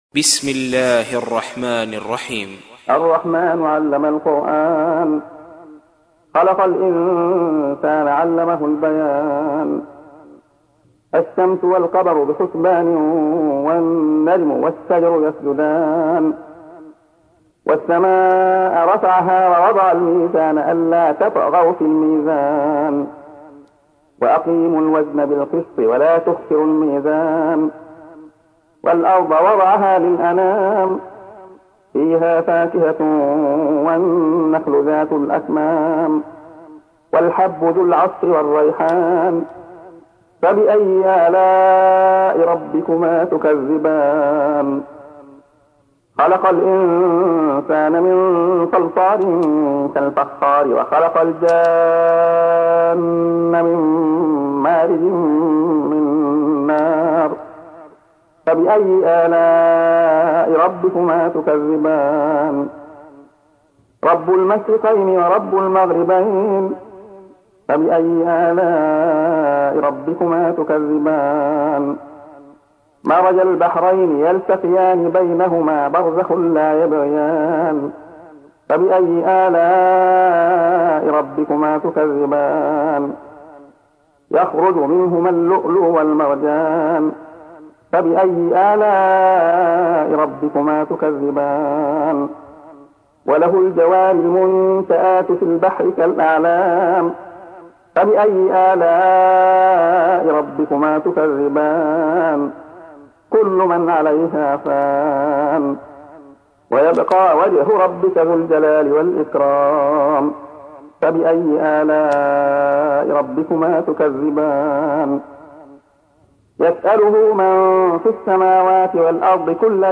تحميل : 55. سورة الرحمن / القارئ عبد الله خياط / القرآن الكريم / موقع يا حسين